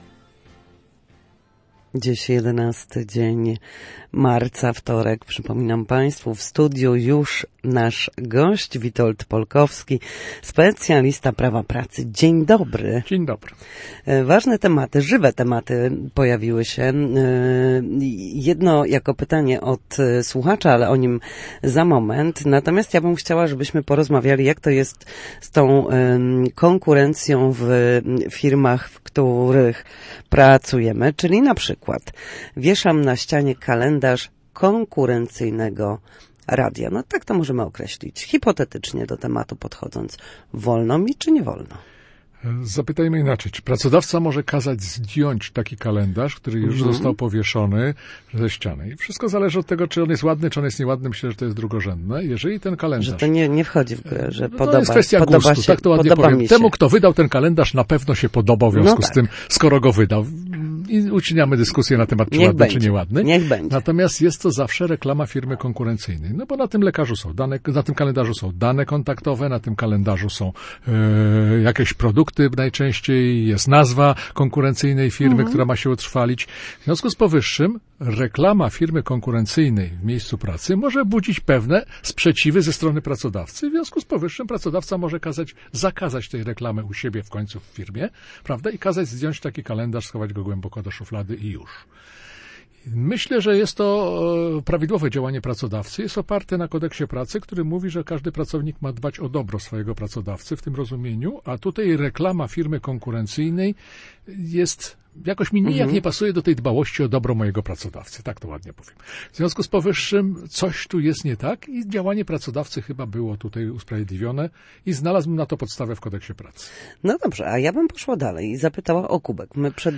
W każdy wtorek po godzinie 13:00 na antenie Studia Słupsk przybliżamy zagadnienia dotyczące prawa pracy.